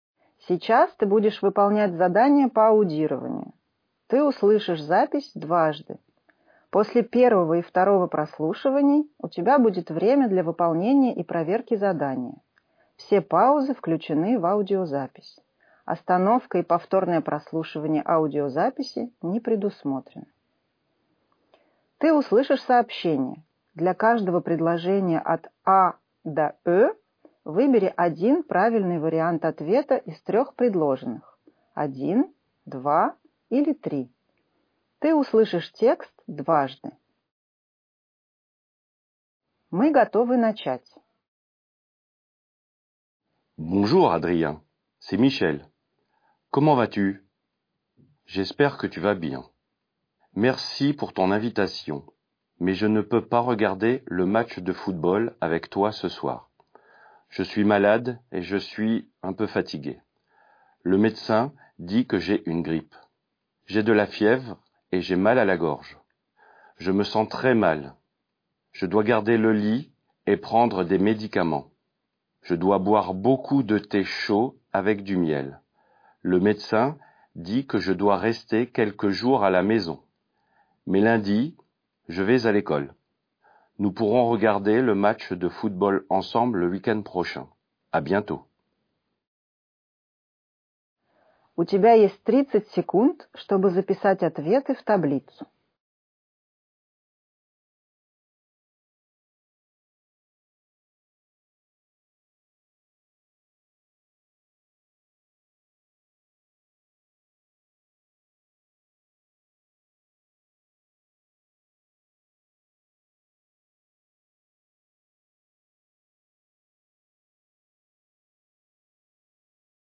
Ты услышишь сообщение.
Ты услышишь текст дважды.